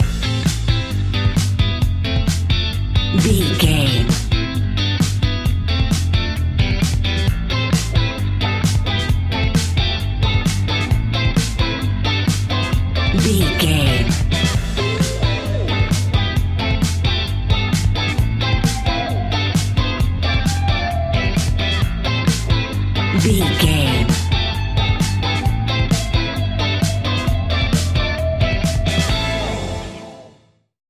Ionian/Major
house
electro dance
synths
techno
trance
instrumentals